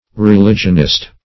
Religionist \Re*li"gion*ist\, n.